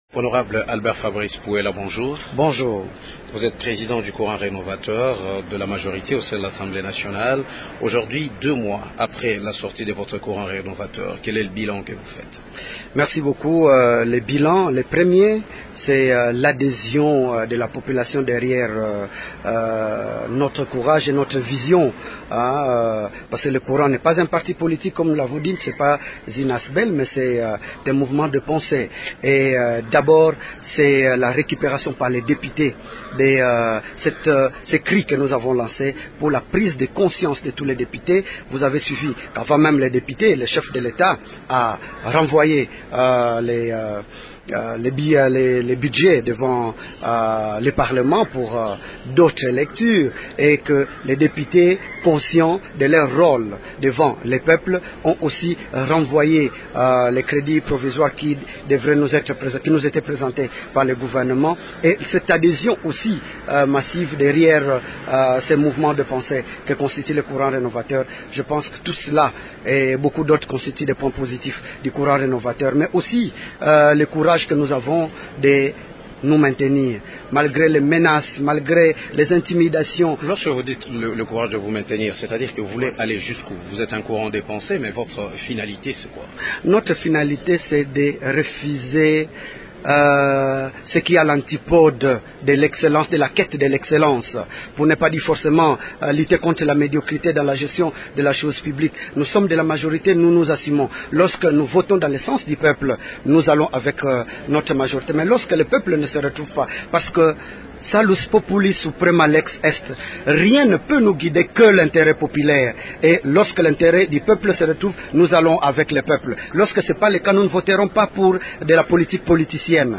Ce groupe de députés nous livre sa lecture de la scène politique nationale au lendemain de la conférence de presse du 1er ministre Adolphe Muzito. Albert Fabrice Puela est notre invité du jour.